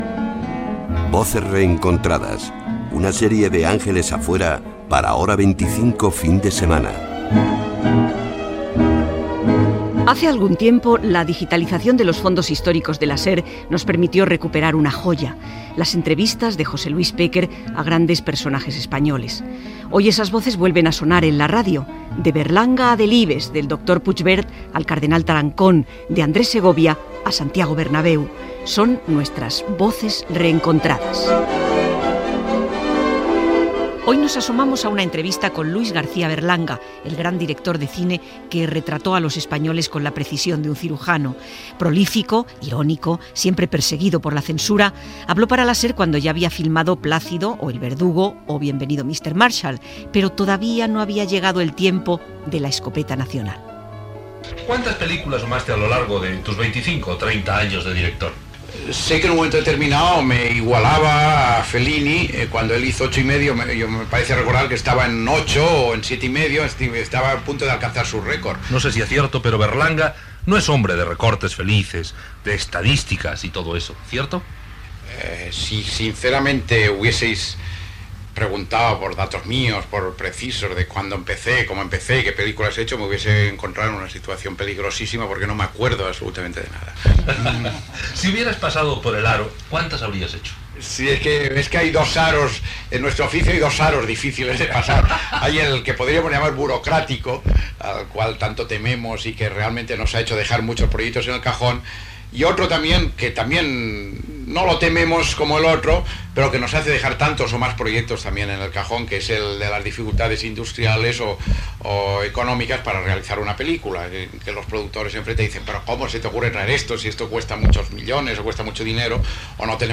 Secció "Voces reencontradas" amb una entrevista de José Luis Pécker al director de cinema Luis García-Berlanga, emesa al programa "Gente Importante", a la dècada de 1970